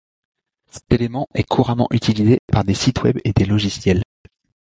/wɛb/